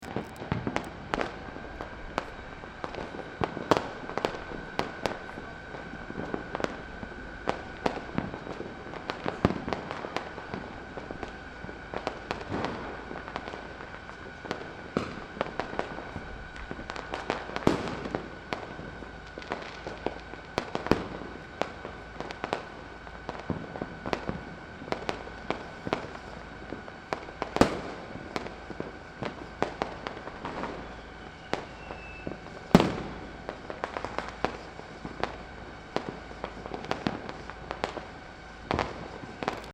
03/11/2013 23:00 Ce soir c'est Diwali, la fête des lumières. Je passe un moment à papoter avec un écrivain bouthanais en contemplant le chaos des feux d'artifices et des pétards, qui explosent dans tous les sens au milieu des rickshaw, des passants et des vaches.
feudartifice.mp3